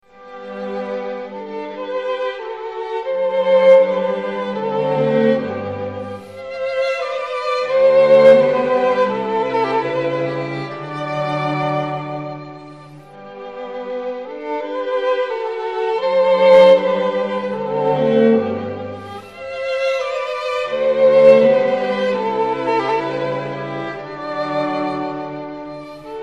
HAYDN (Joseph) Quatuor a cordes op76 n3 (hymne allemand) - BOURDON, les mendiants-old.mp3